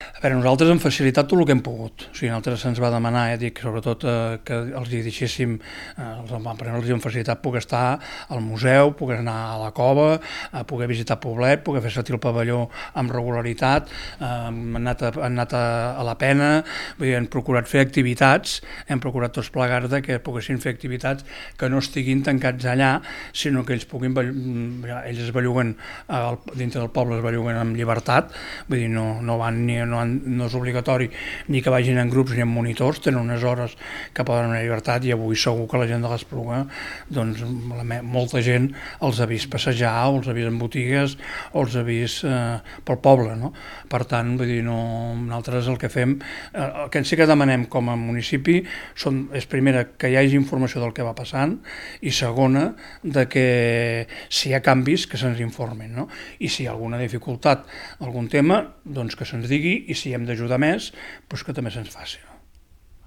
ÀUDIO: Rovira explica la col·laboració de l’Ajuntament